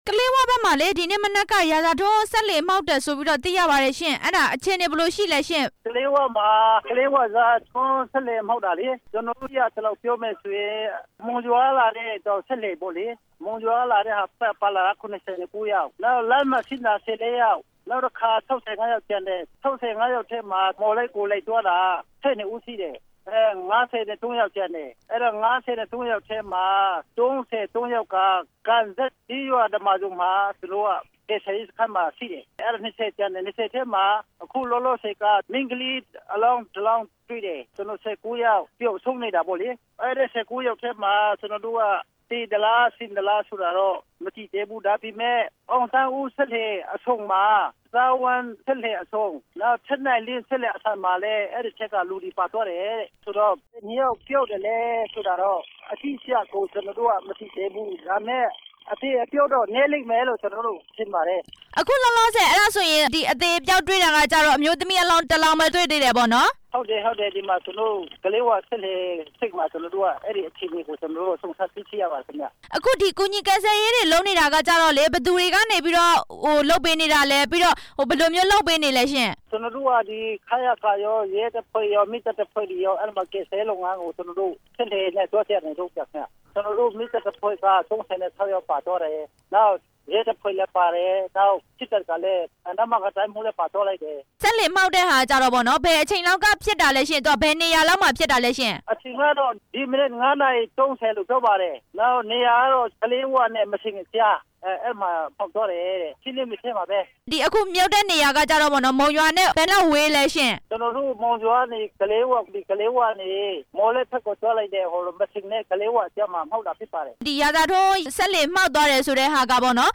ရာဇာထွန်း အမြန်ရေယာဉ် တိမ်းမှောက်မှု မေးမြန်းချက်